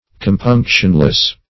Compunctionless \Com*punc"tion*less\, a.